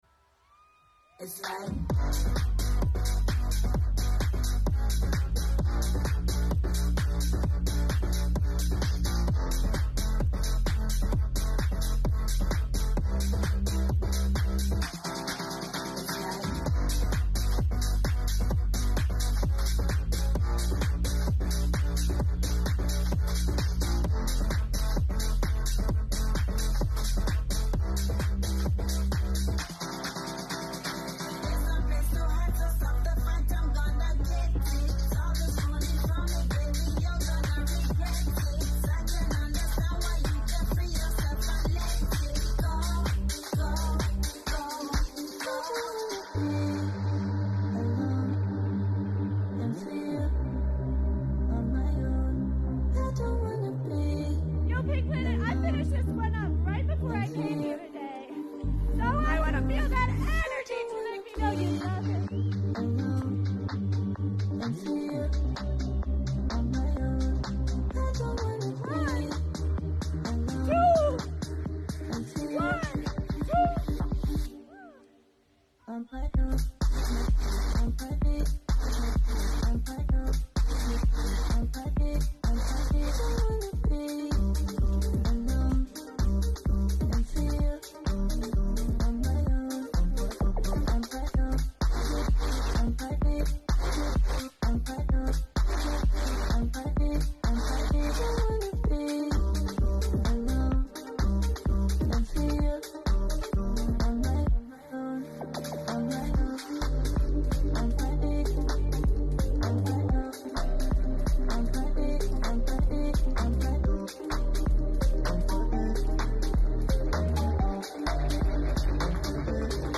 DJ Mixes